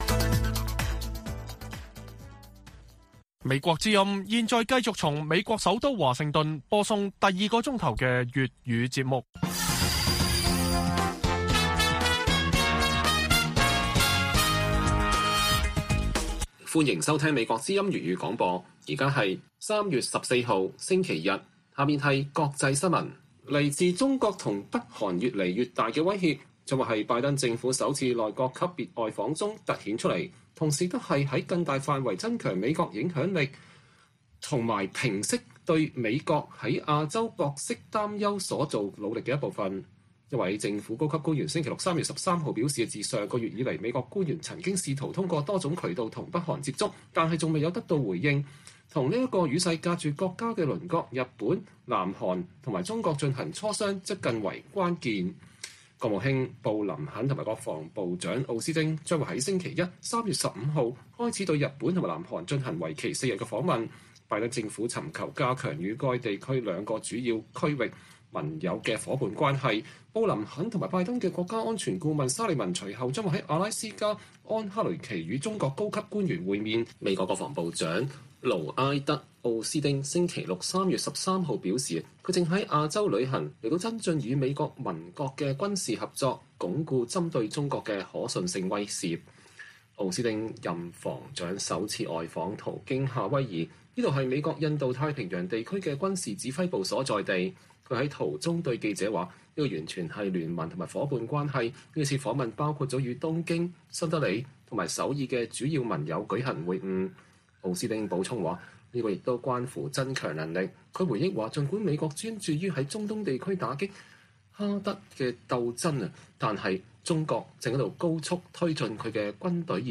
粵語新聞 晚上10-11點